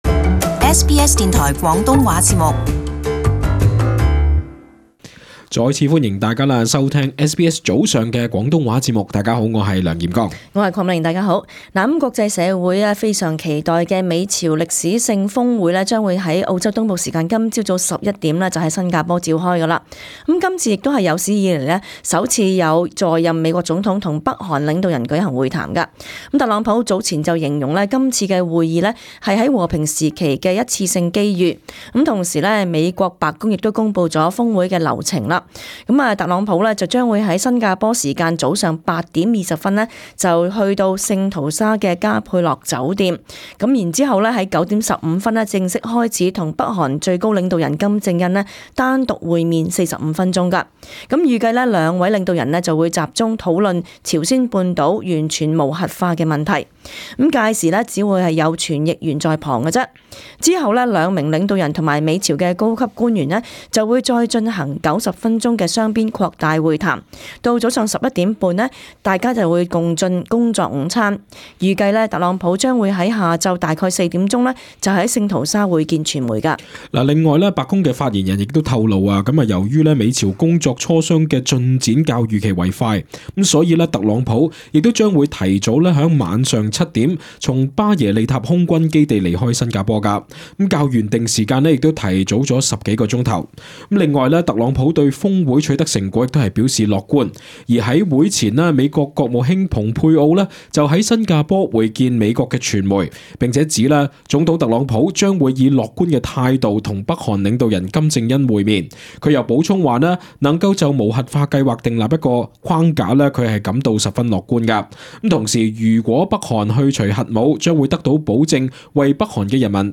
【時事報導】美朝峰會討論朝鮮半島「完全無核化」